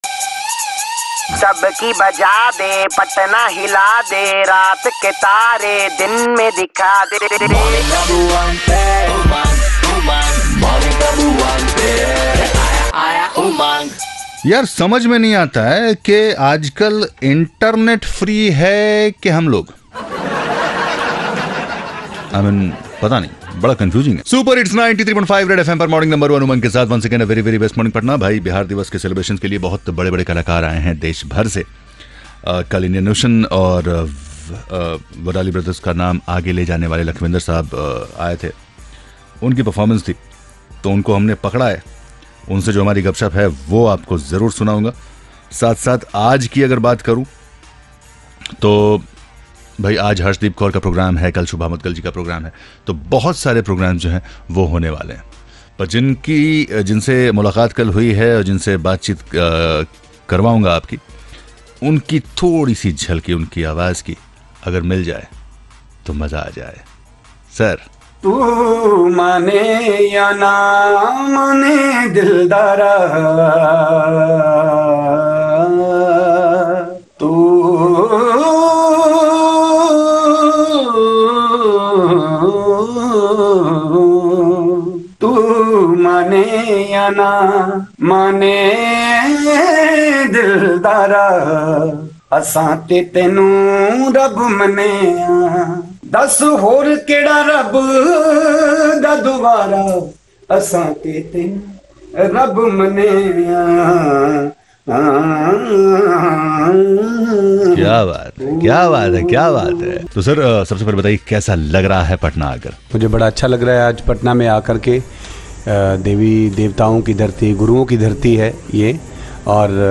Lakhwinder Wadali's Interview